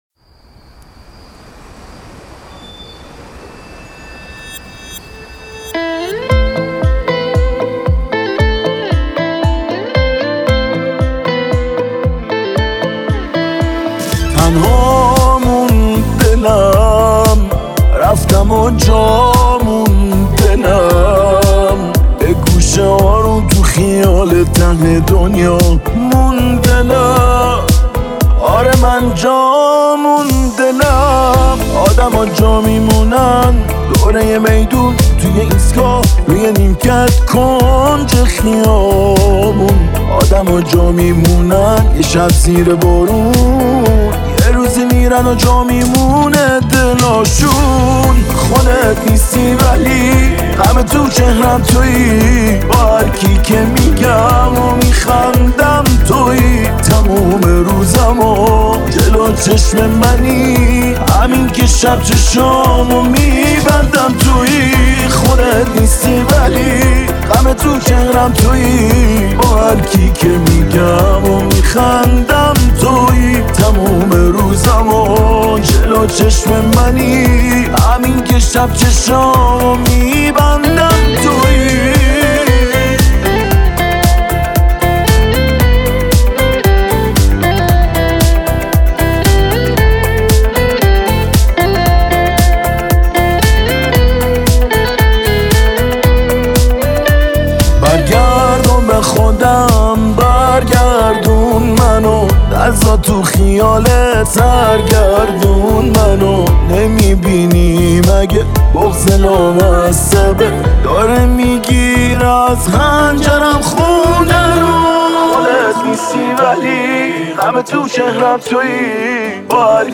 پاپ عاشقانه